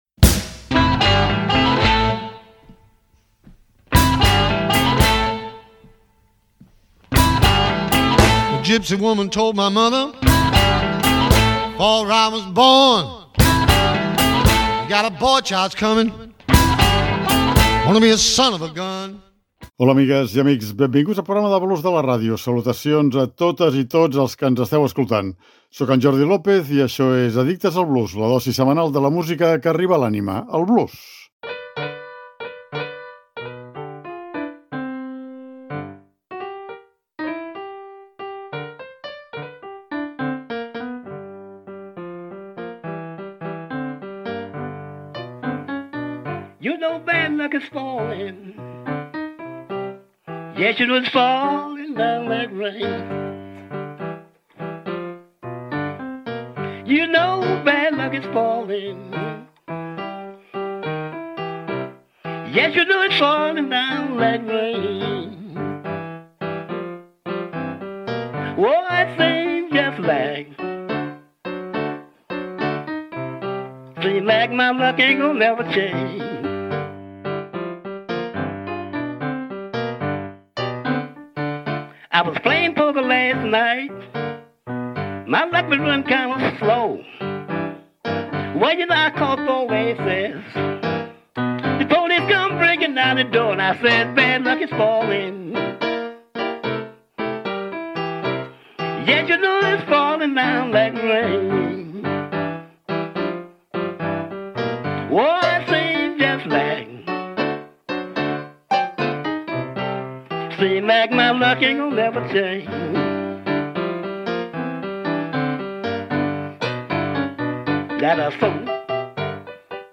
Avui ens endinsarem en el “Detroit Blues”, molt similar al “Chicago Blues” distingint-se el seu so del Delta blues gràcies a l’amplificació dels instruments i a un ordre més eclèctic d’aquests, incloent-hi el baix i el piano.